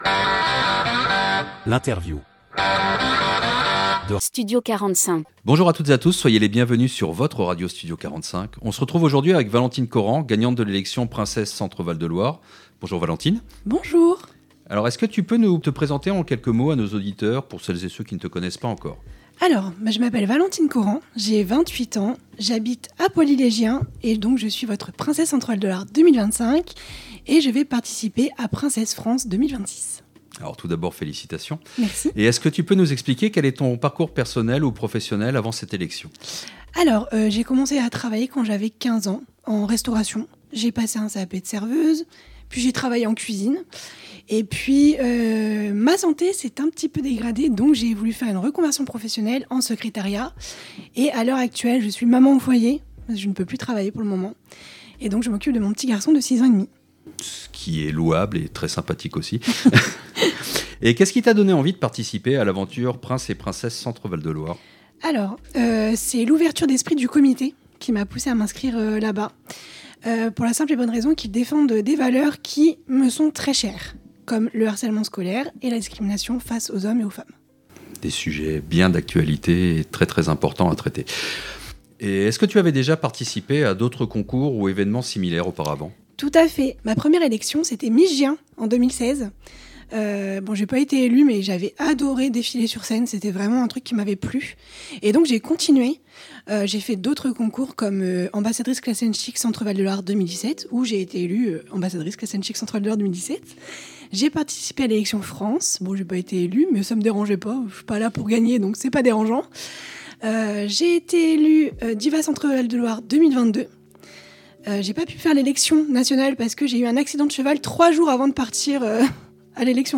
Interview Studio 45